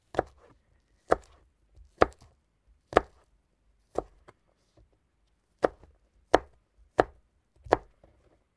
Звук приготовления чеснока - Вариант 2